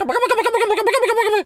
pgs/Assets/Audio/Animal_Impersonations/turkey_ostrich_gobble_13.wav at master
turkey_ostrich_gobble_13.wav